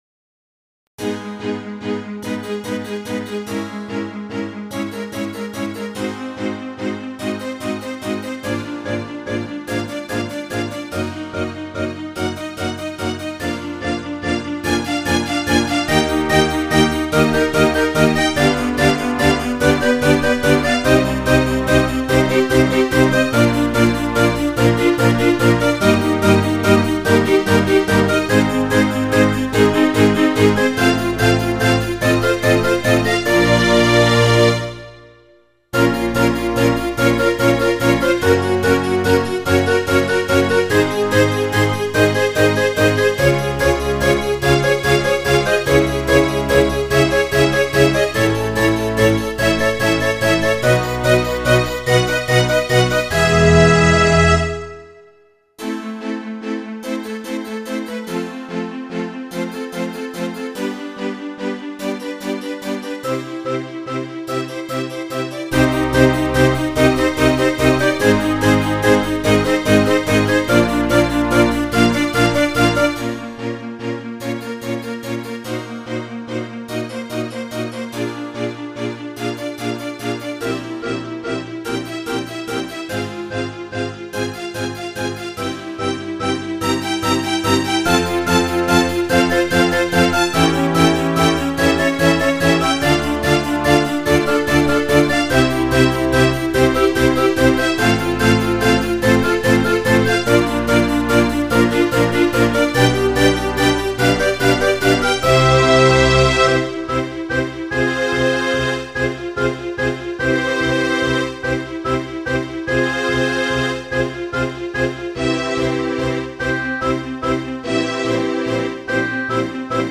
I love the sound of the harpsichord with strings.